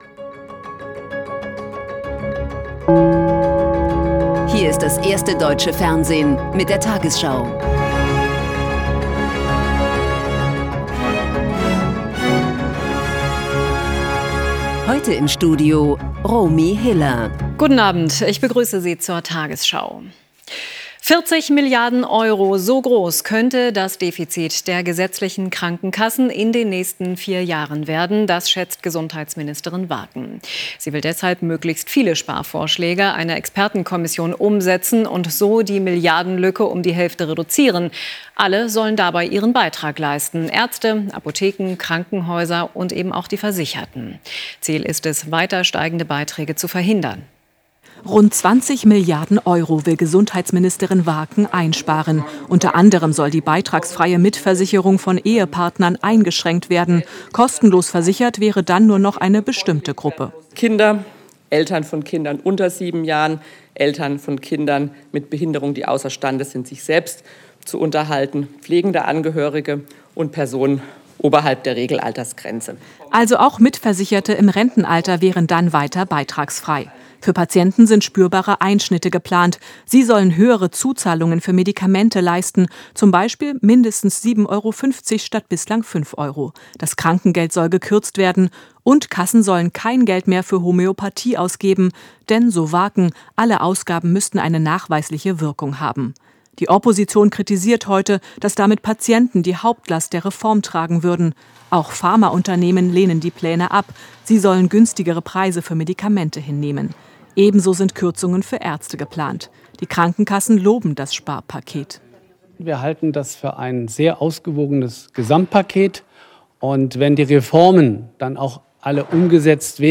tagesschau 20:00 Uhr, 14.04.2026 ~ tagesschau: Die 20 Uhr Nachrichten (Audio) Podcast